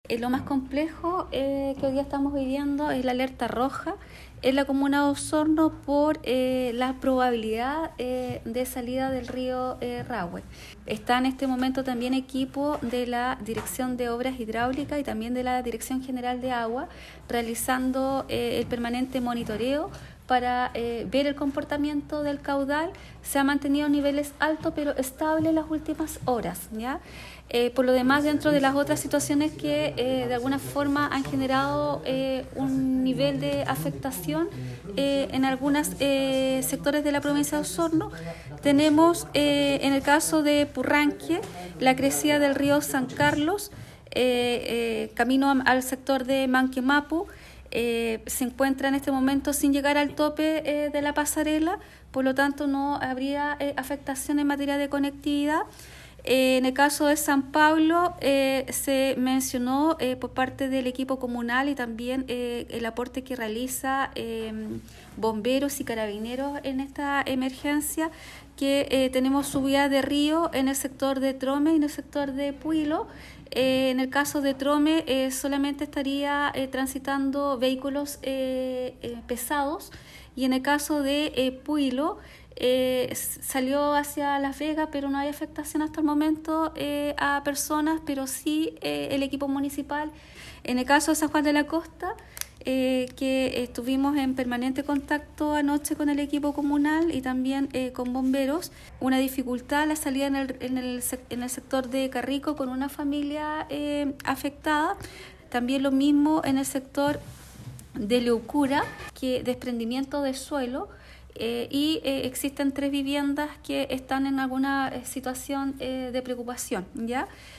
A causa de la Alerta Roja emanada por Senapred por peligro de desborde de ríos en la comuna de Osorno, esta mañana en la Delegación Presidencial provincial se realizó una sesión de emergencia del Comité de Gestión y Respuesta ante Desastres, Cogrid. Este es el resumen de la situación actual que existe en la Provincia, como lo explica la Delegada Presidencial Provincial Claudia Pailalef.